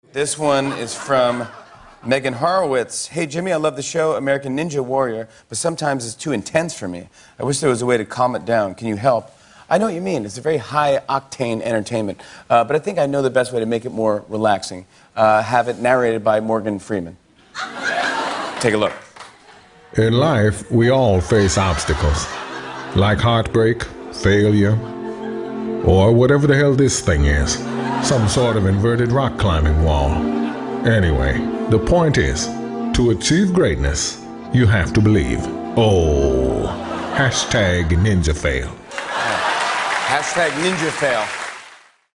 Morgan Freeman Narrates American Ninja Warrior